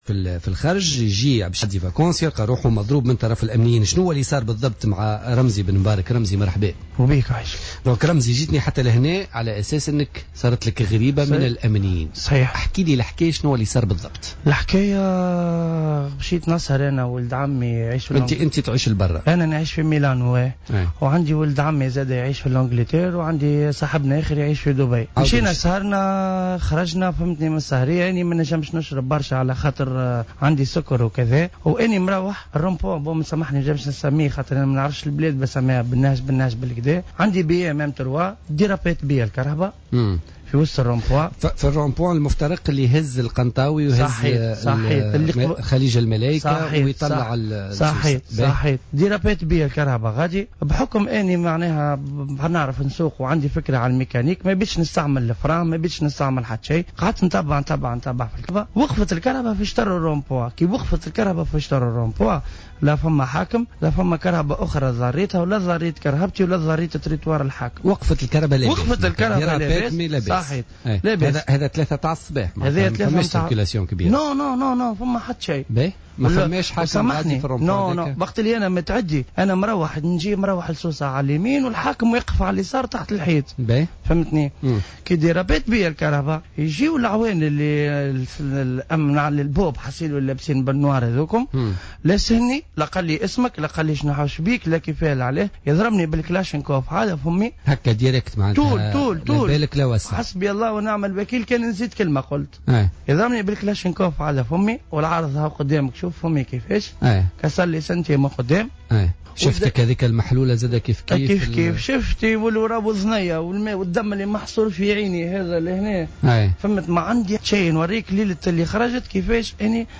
تونسي مقيم بالخارج يروي تفاصيل الإعتداء عليه من قبل أمني في سوسة